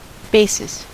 Ääntäminen
Synonyymit base root Ääntäminen : IPA : [ˈbeɪ.sɪs] US : IPA : [ˈbeɪ.sɪs] Haettu sana löytyi näillä lähdekielillä: englanti Määritelmät Substantiivit A starting point , base or foundation for an argument or hypothesis .